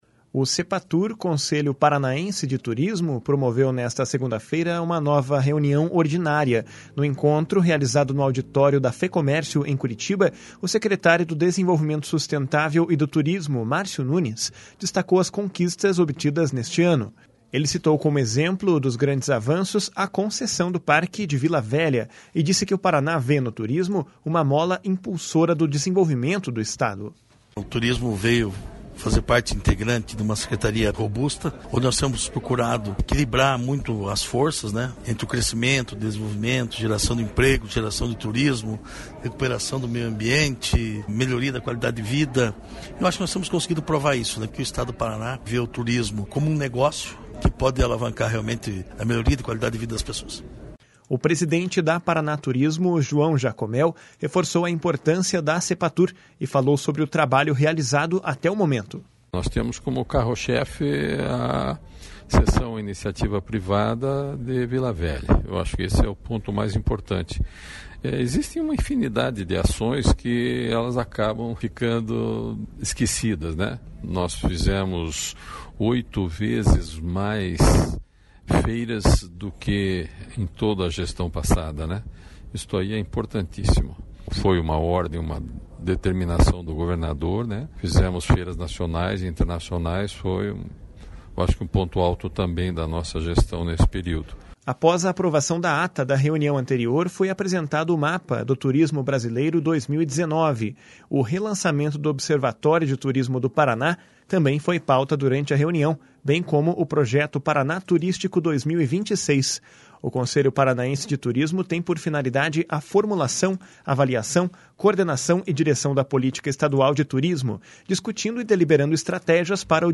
No encontro, realizado no auditório da Fecomércio, em Curitiba, o secretário do Desenvolvimento Sustentável e do Turismo, Márcio Nunes, destacou as conquistas obtidas neste ano. Ele citou como exemplo dos grandes avanços a concessão do Parque de Vila Velha, e disse que o Paraná vê no turismo uma mola impulsora do desenvolvimento do Estado.// SONORA MARCIO NUNES.//
O presidente da Paraná Turismo, João Jacob Mehl, reforçou a importância do Cepatur e falou sobre o trabalho realizado até o momento.// SONORA JACOB MEHL.//